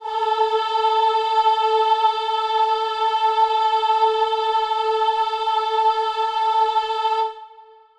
Choir Piano
A4.wav